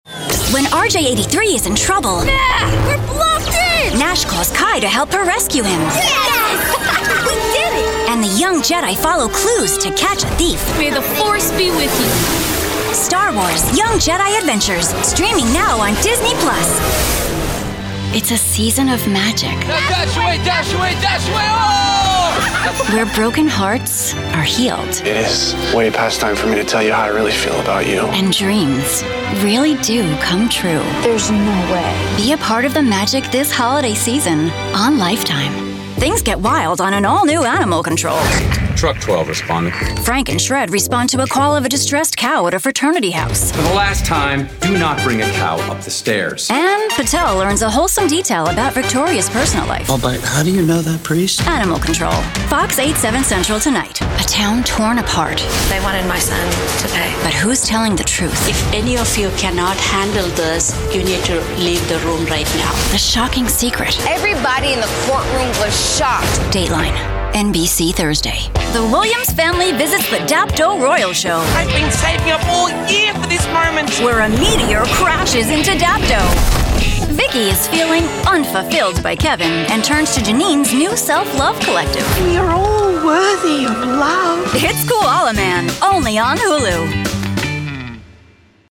Female
Bright, Bubbly, Friendly, Warm, Confident, Natural, Young, Approachable, Conversational, Energetic, Soft, Upbeat
Microphone: TLM 102, RE20
Audio equipment: Whisper Room Booth, Apollo Twin Interface, DBX 286s preamp/processor, Aventone speakers